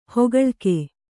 ♪ hogaḷke